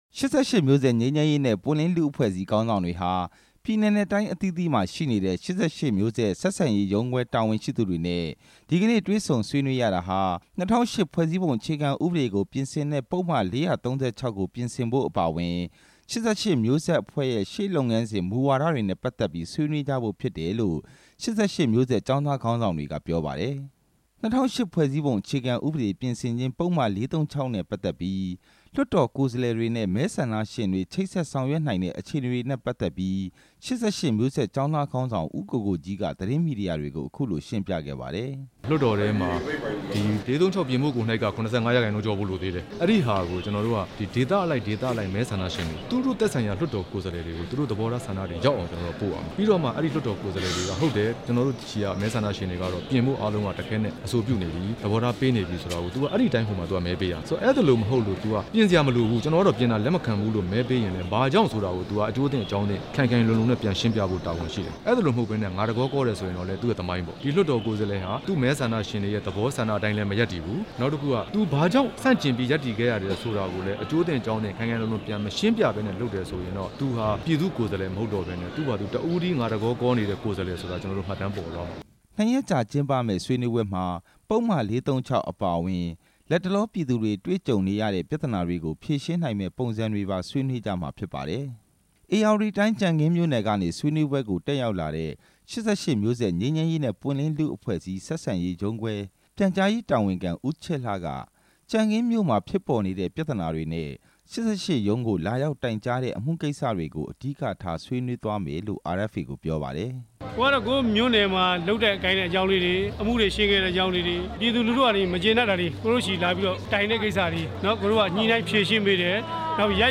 တွေ့ဆုံဆွေးနွေးပွဲအကြောင်း တင်ပြချက်